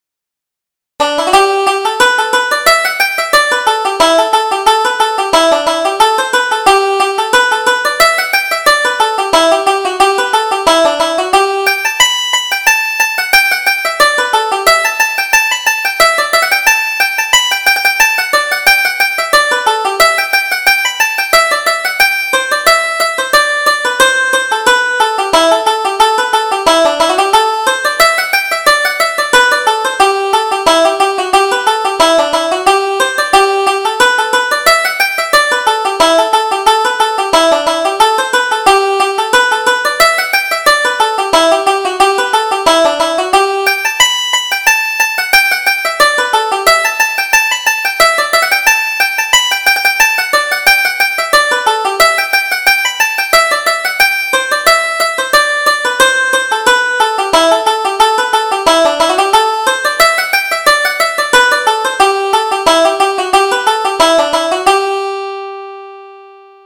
Reel: Over the Moor to Maggie